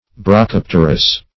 brachypterous - definition of brachypterous - synonyms, pronunciation, spelling from Free Dictionary
Brachypterous \Bra*chyp"ter*ous\, a. [Gr. ? : cf. F.